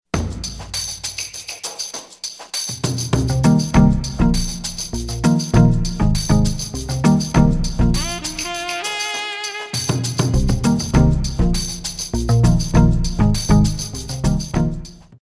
Dance 79a